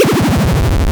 gameover4.wav